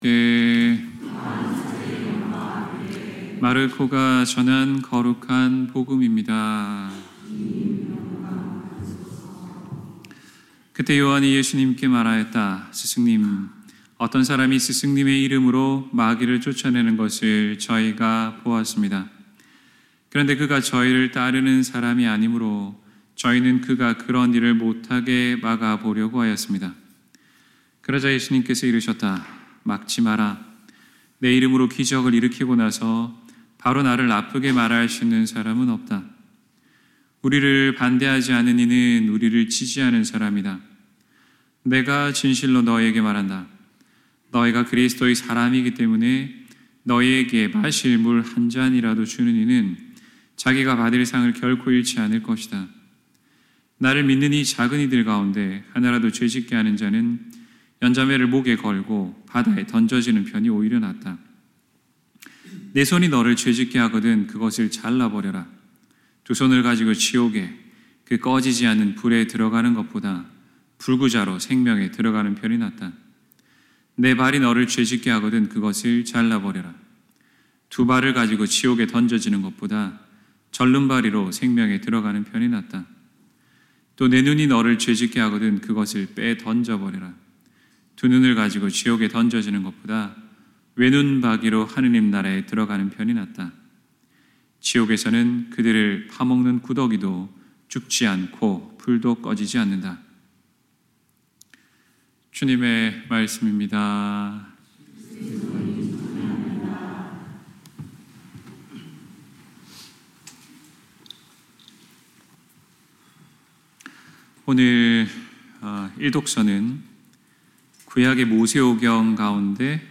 2024년 9월 29일 연중 제26주일 신부님 강론